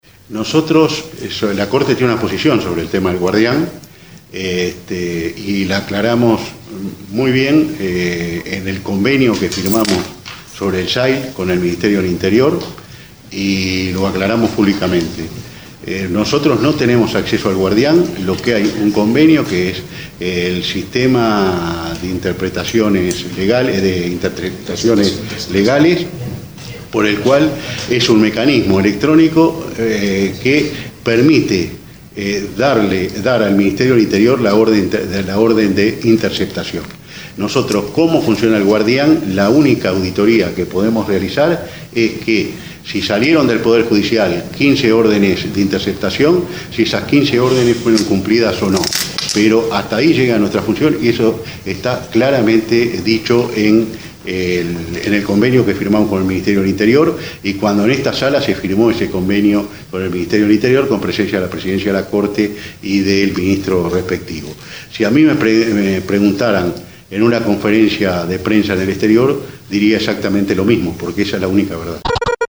El Pte. de la SCJ explica fallo.